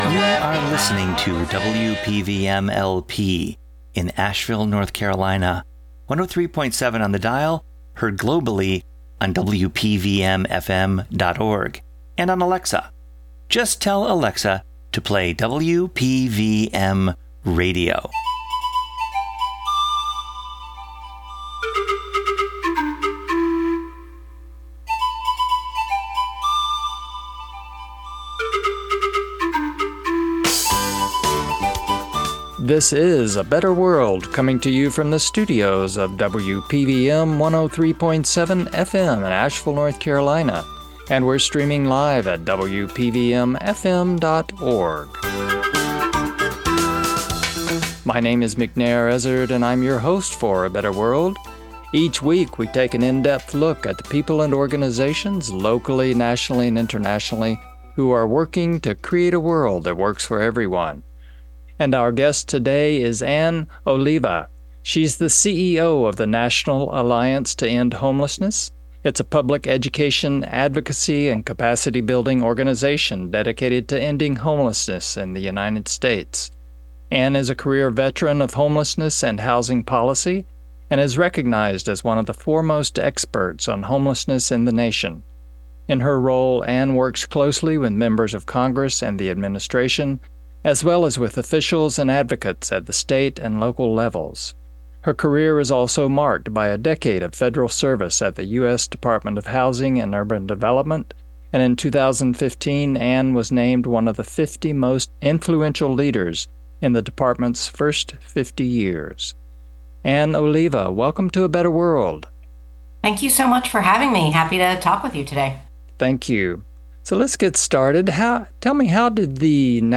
Join our conversation with National Alliance to End Homelessness, exploring solutions, policy, and real strategies to end homelessness in the United States.